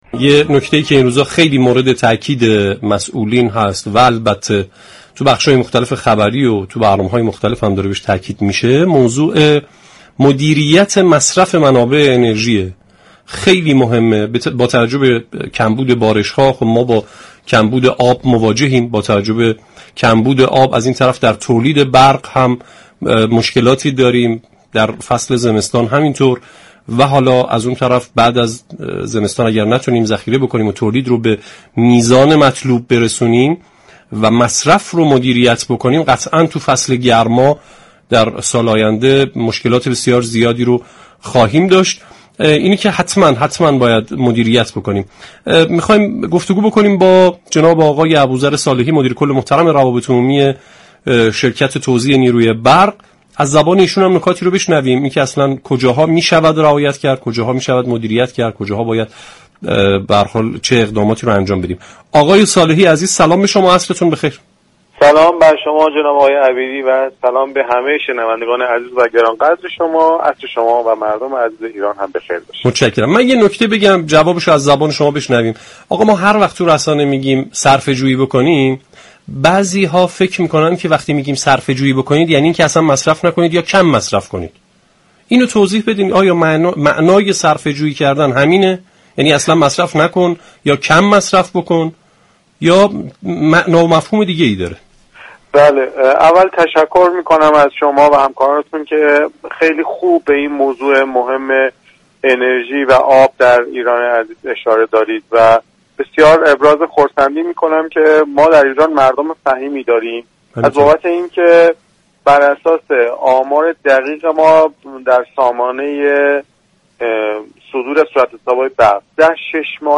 در گفتگو با برنامه والعصر رادیو قرآن